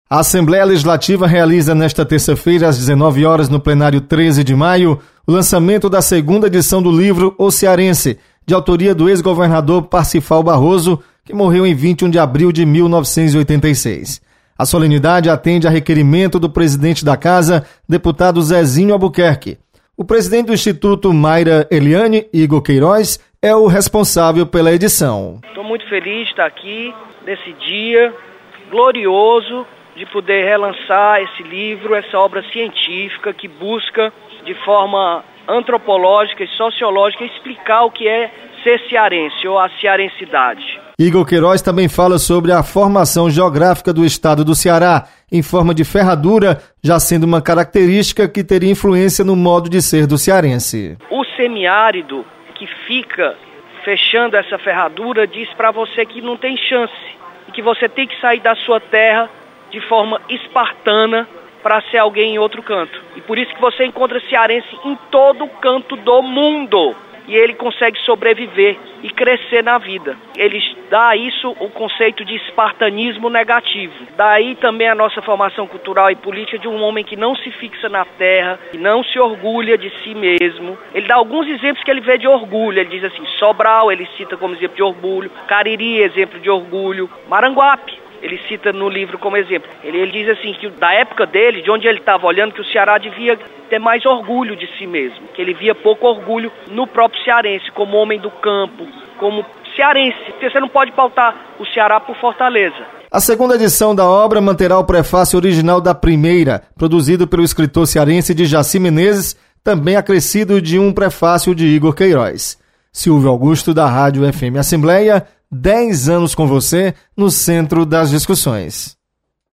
Segunda edição do livro "O Cearense" será lançada nesta terça na Assembleia Legislativa. Repórter